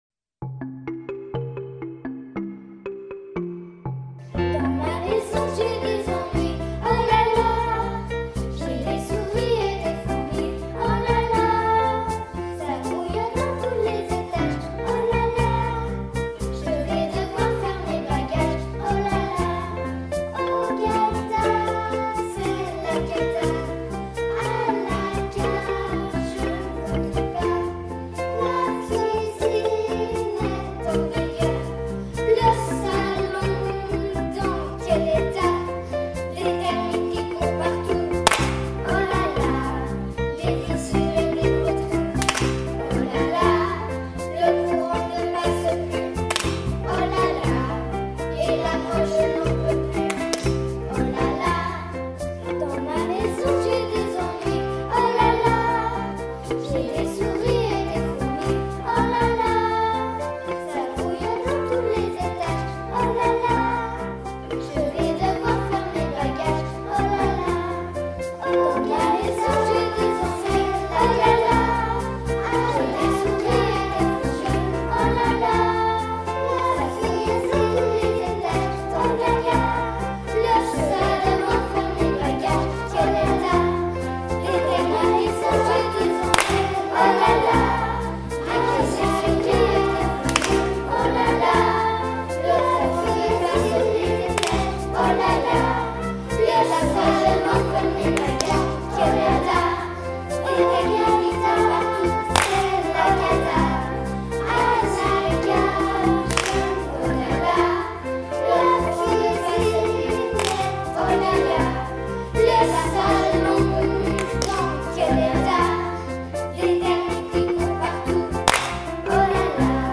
chanson – partitionplay-back